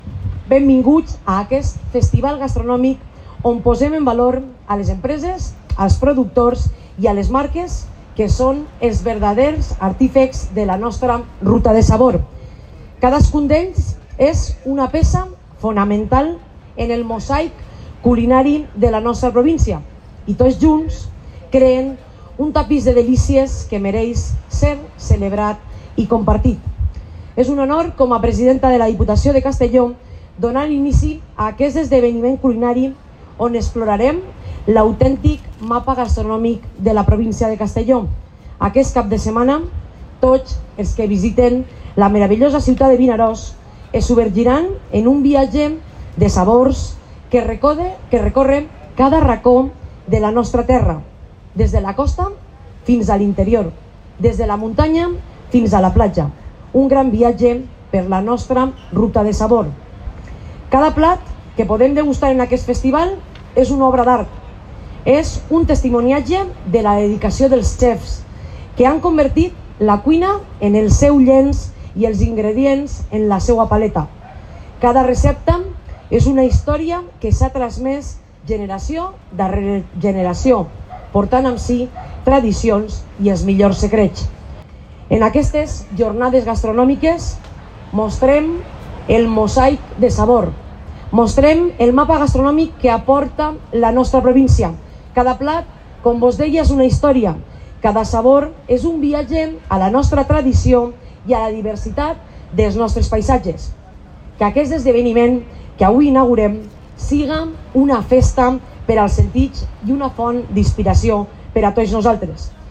• La presidenta de la Diputació inaugura el II Festival Gastronòmic Castelló Ruta de Sabor que fins al diumenge reunirà a Vinaròs els millors productes i sabors de la província
Presidenta-Marta-Barrachina-inauguracion-II-Festival-Gastronomico-Castel.-Sabor.mp3